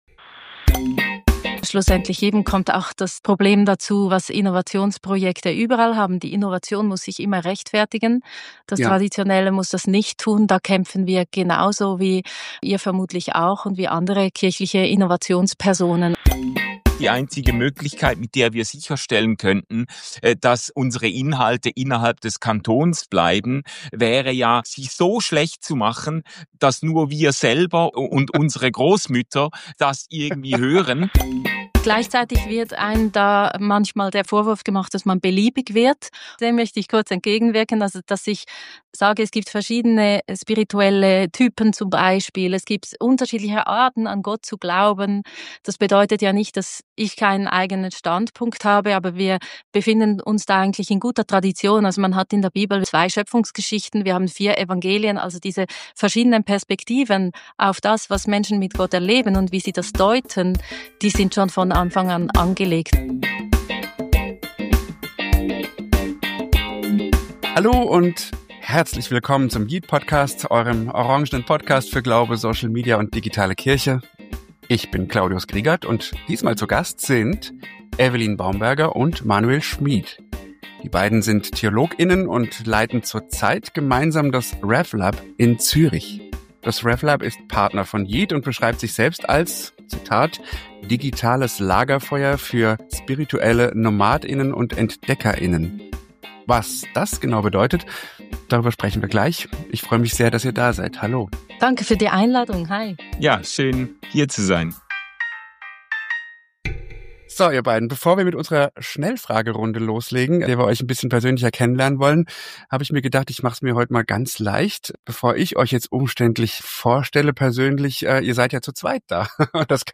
Social Media für Glaube und Kirche - das ist der yeet-Podcast: yeet-Redakteur* innen befragen Expert* innen und Influencer* innen und begeben sich auf die Suche nach den großen und kleinen Perspektiven auf die digitalen Kirchen-Räume und Welten in den Sozialen Medien.